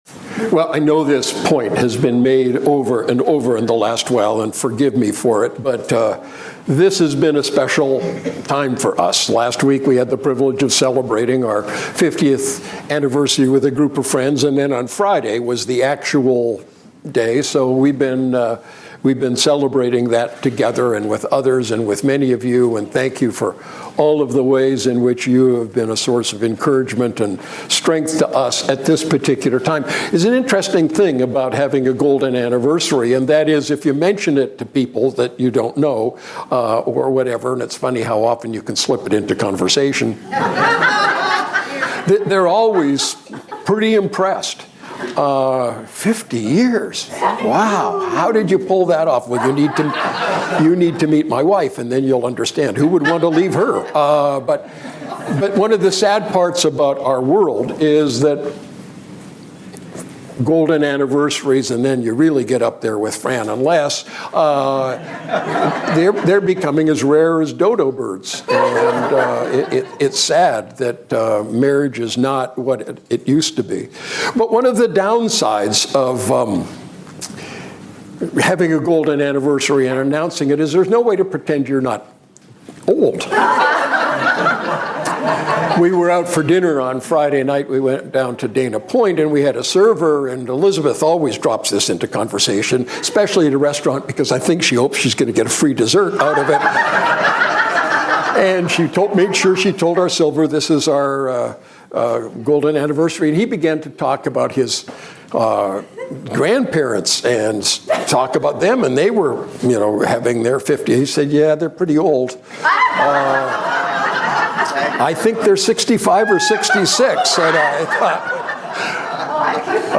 Message: “Fear Not”